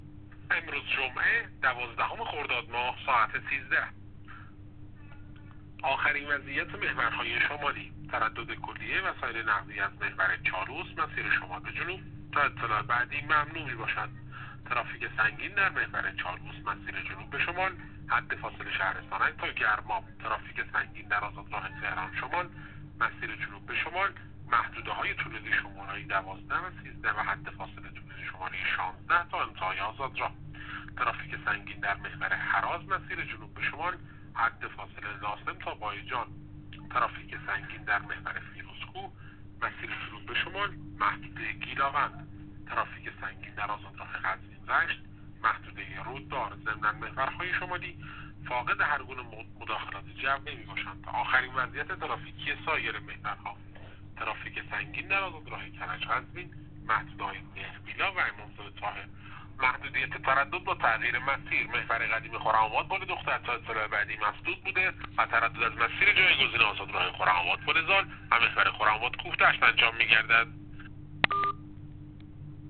گزارش رادیو اینترنتی از آخرین وضعیت ترافیکی جاده‌ها تا ساعت ۱۳ دوازدهم خرداد؛